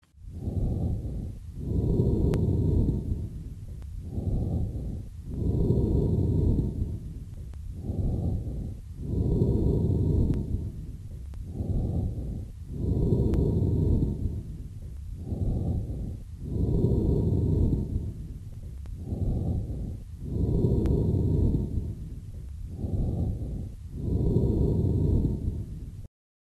Звуки отдышки
Звук амфорического дыхания при крупном абсцессе левого легкого